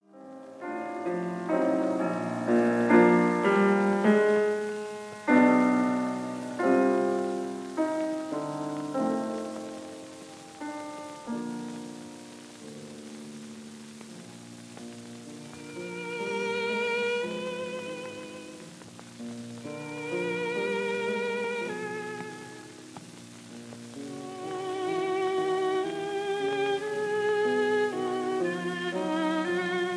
Recorded in Zurich 1947